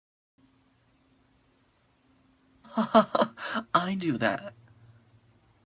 haha-i-do-that-192-kbps.mp3